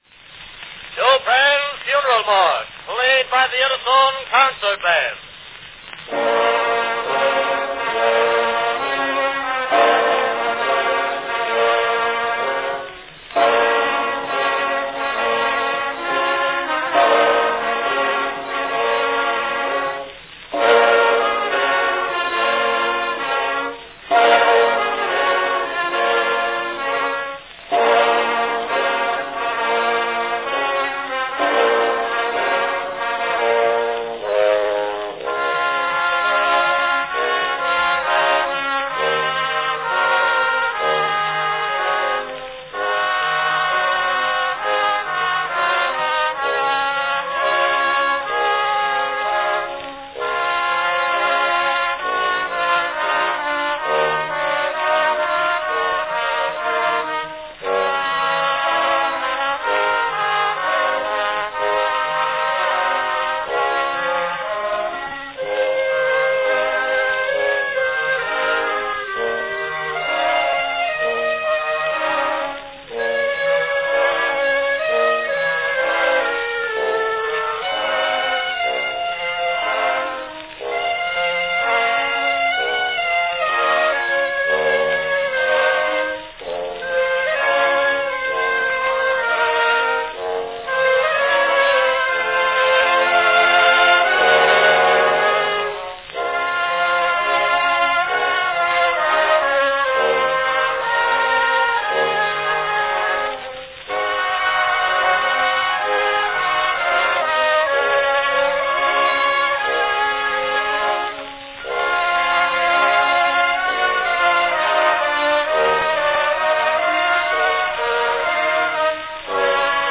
Category Band